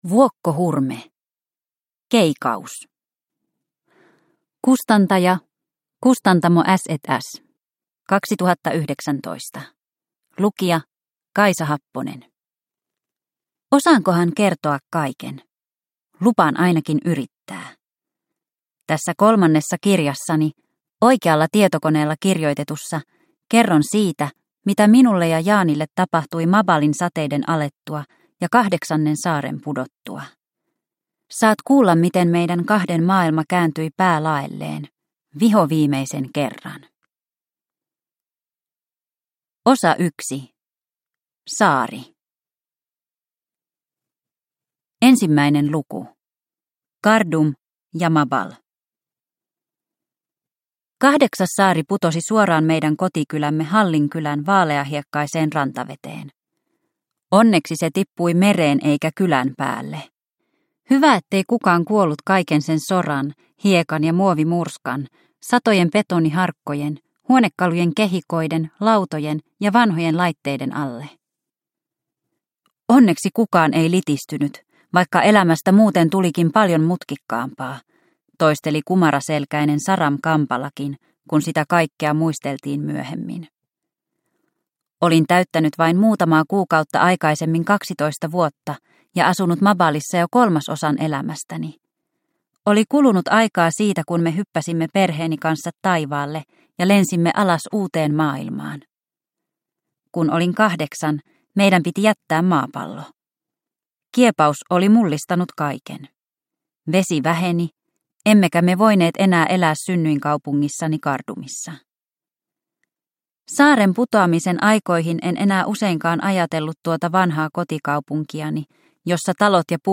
Keikaus – Ljudbok – Laddas ner